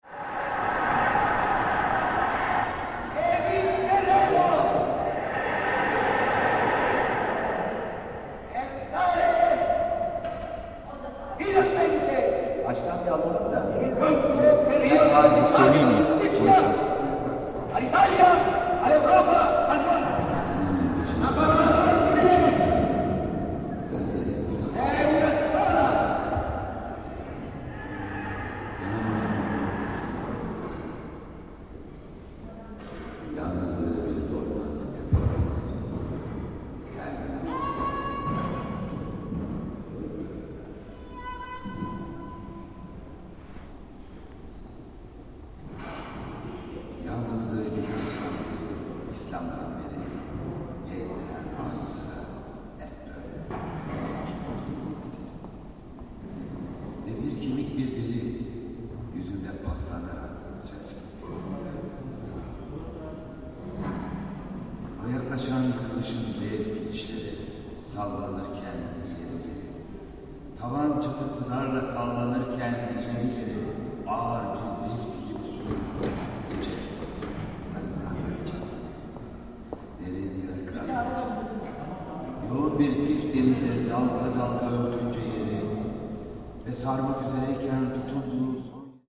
Soundcheck at Hagia Eirene
Chairs are being arranged, PAs checked, people building up the lights and testing them.
The vaults of the byzantine church echo with the transphonics of history.